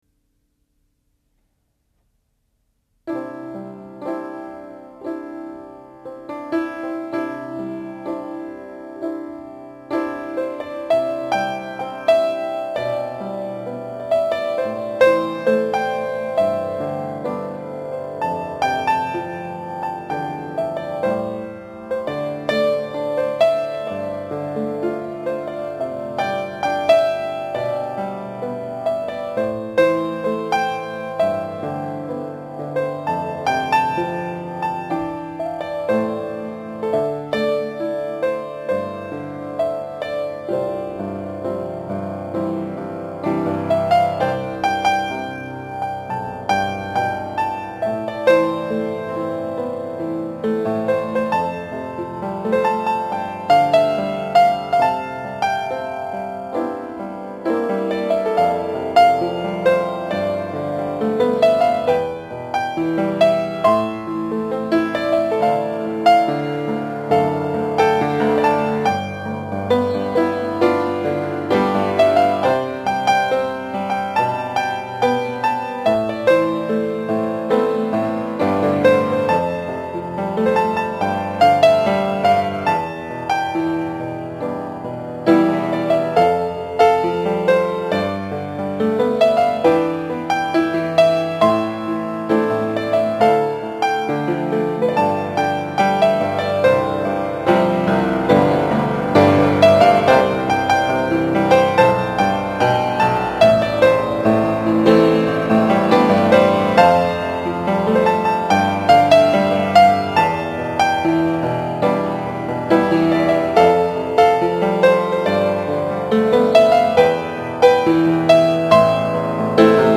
钢琴伴奏 | 吉他谱 | 简谱和弦
lts30_song_piano.mp3